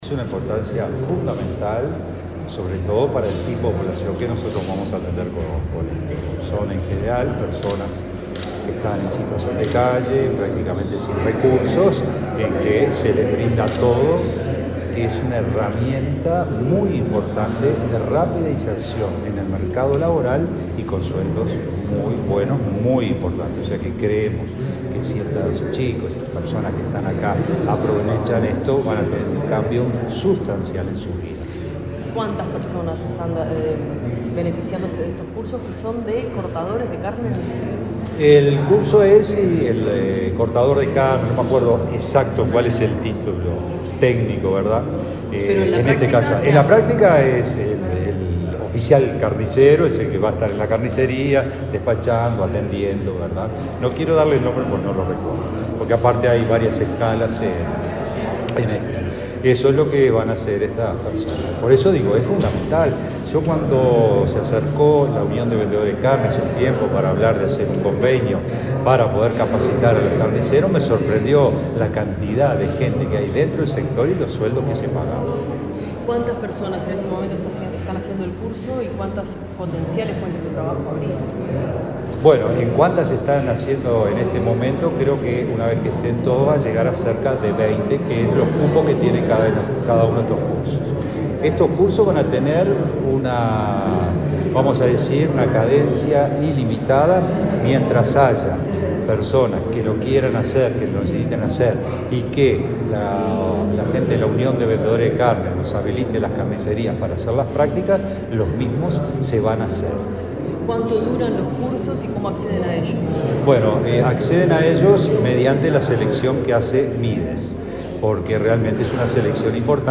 Declaraciones del director general de UTU
El director general de UTU, Juan Pereyra, dialogó con la prensa luego de participar del acto de comienzo de cursos para carniceros de esa casa de